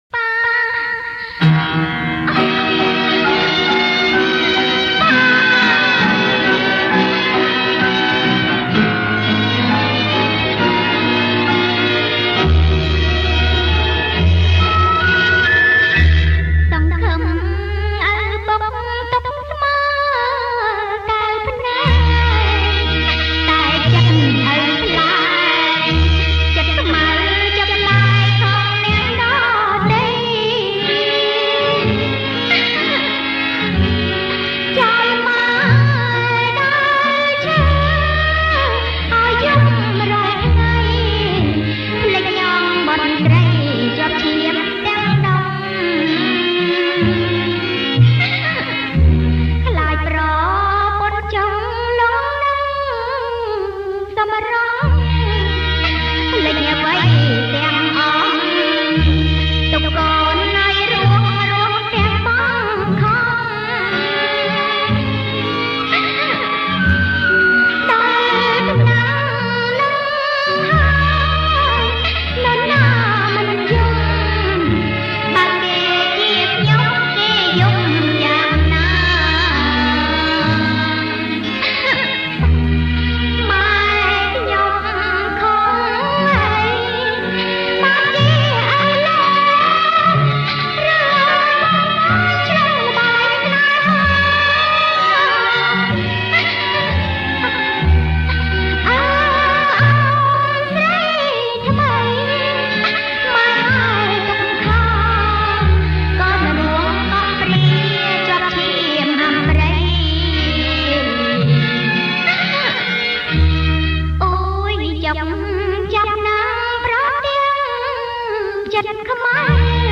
• ប្រគំជាចង្វាក់ Slow